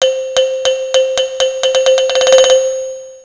Index of /phonetones/unzipped/Motorola/PEBL-VU20/System Sounds/Camera
Timer_3sec.wav